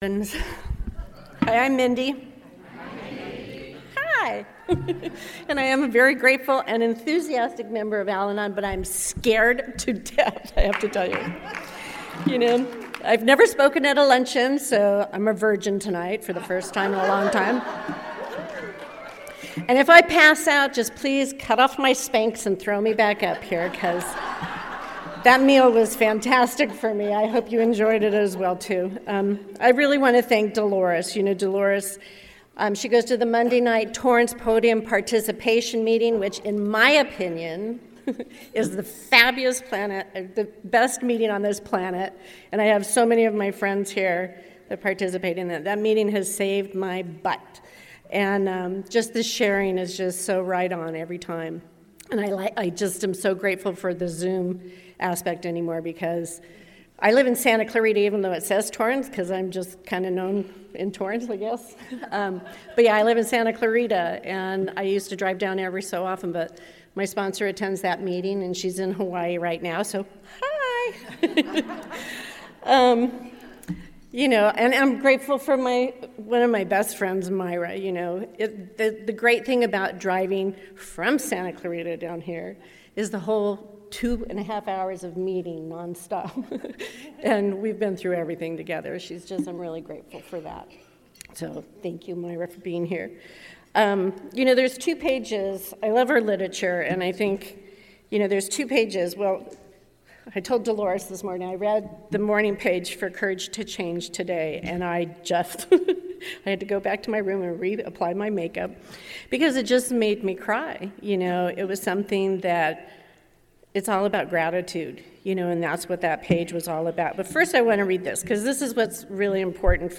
Southern California AA Convention - Al-Anon - 2021